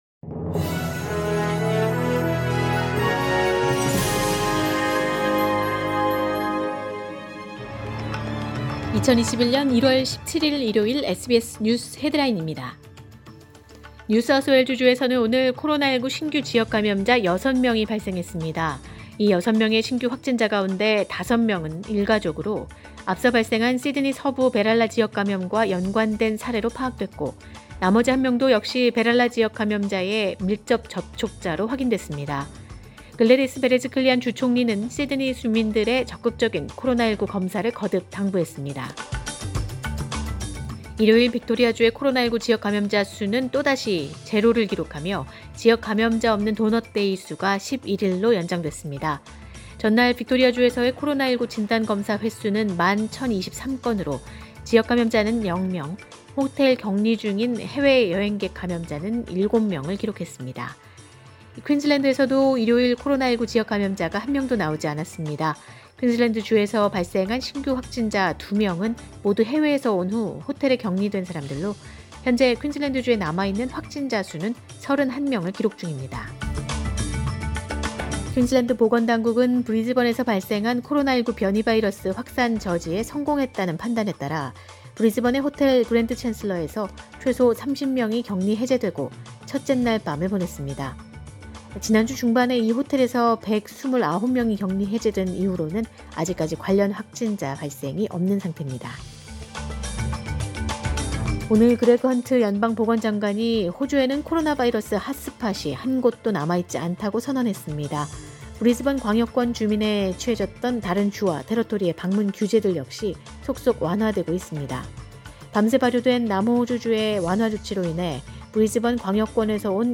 2021년 1월 17일 일요일 SBS 뉴스 헤드라인입니다.